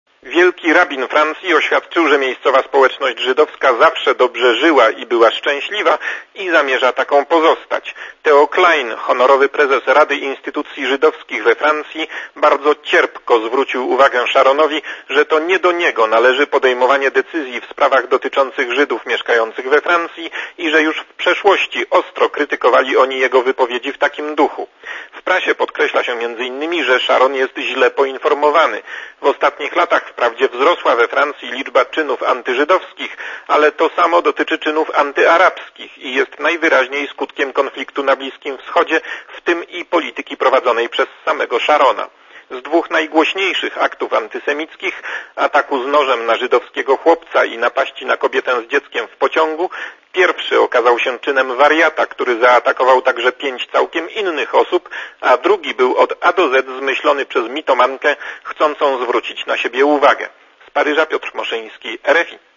Korespondencja z Paryża